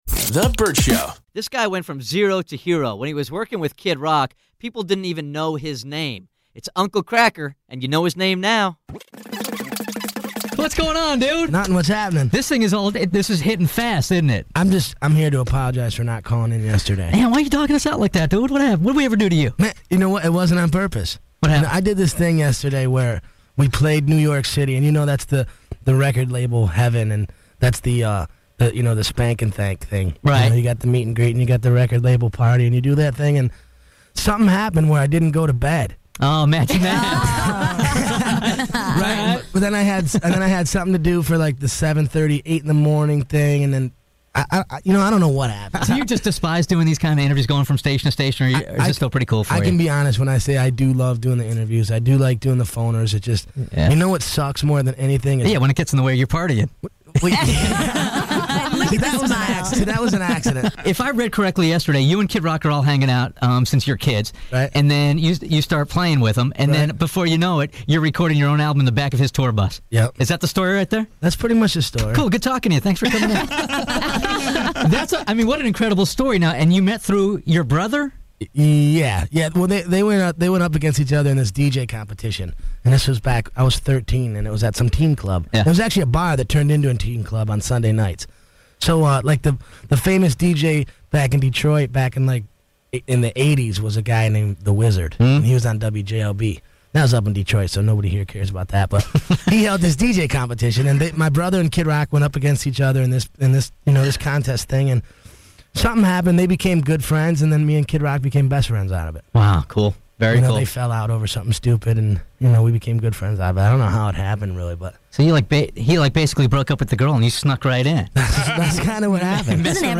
Vault: Interview Uncle Cracker